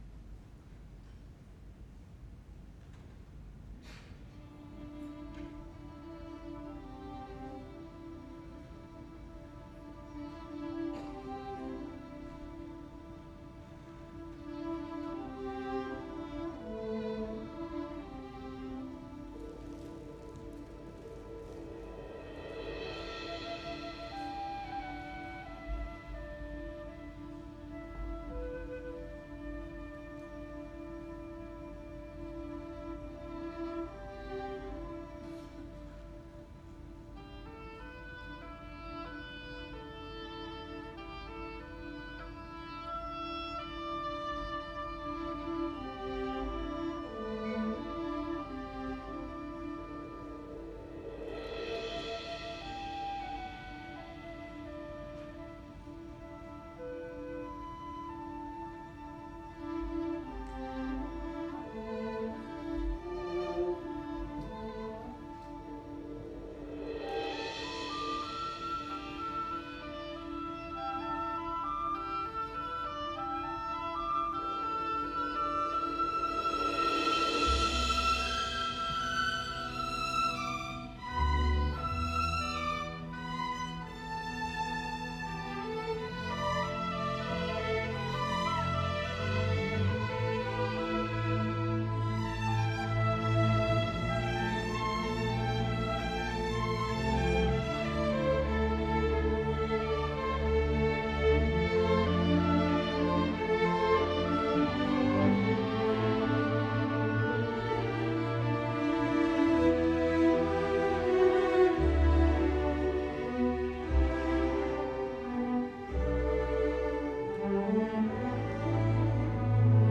for Orchestra (1994)